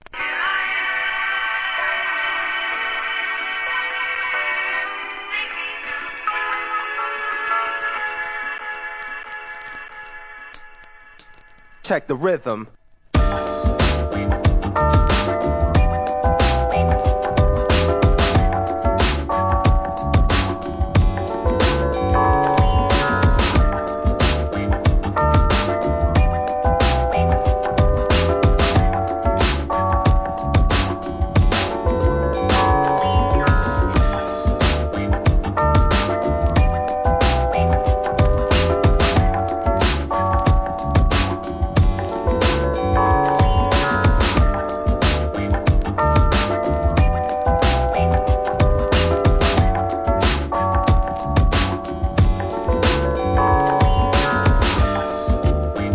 HIP HOP/REGGARE/SOUL/FUNK/HOUSE/
女性スキャットを絶妙に使ったムーディーなボッサ・ ヒップホップ
ブレイクビーツ